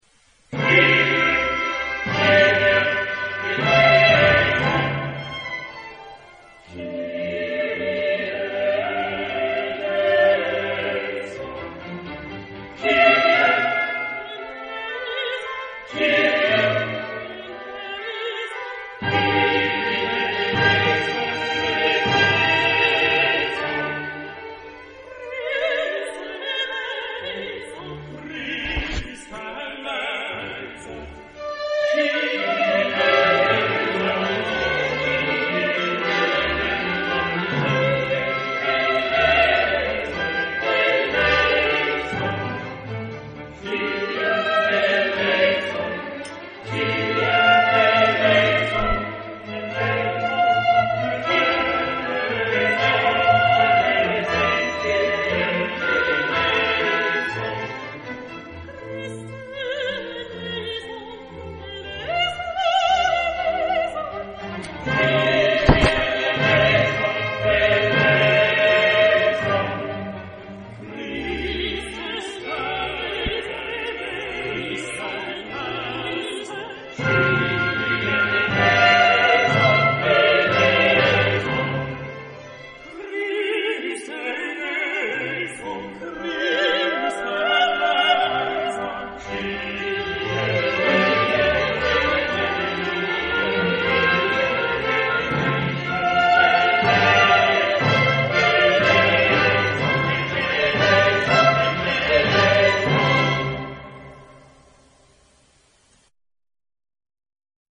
Género/Estilo/Forma: Sagrado ; Clásico ; Misa
Carácter de la pieza : alegre
Tipo de formación coral: SATB  (4 voces Coro mixto )
Solistas : SATB  (4 solista(s) )
Instrumentación: Orquesta  (13 partes instrumentales)
Instrumentos: Oboe (2) ; Trompeta (2) ; Timbala (1) ; Trombón (3) ; Violín I ; Violín II ; Violonchelo (1) ; Contrabajo (1) ; Organo (1)
Tonalidad : do mayor